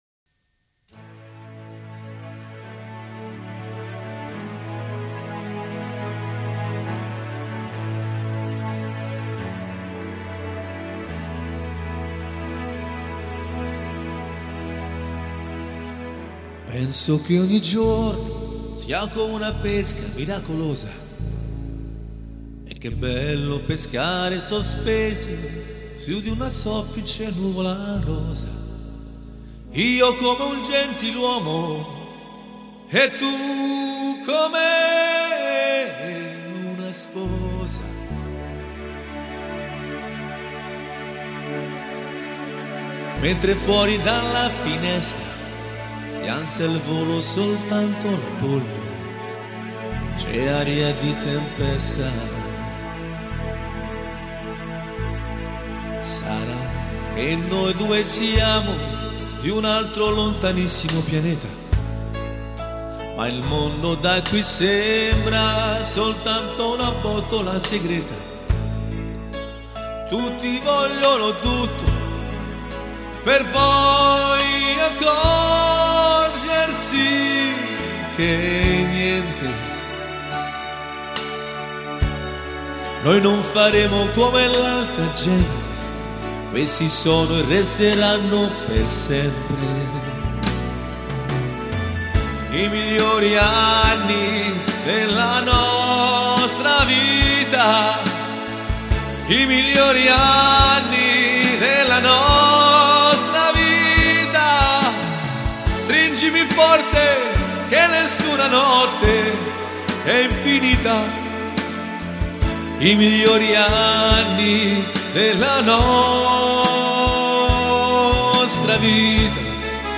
in chiave moderna con tastiera e voce
Serenata moderna e romana (Con tastiera e voce)